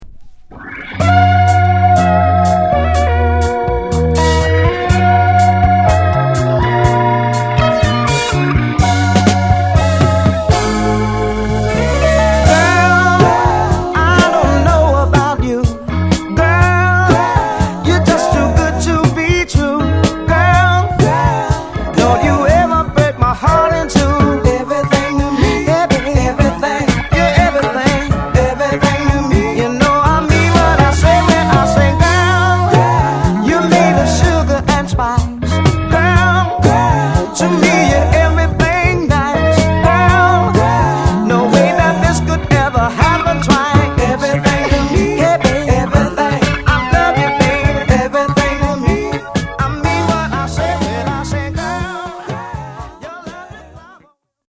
カップリングにはモノラルヴァージョンを収録。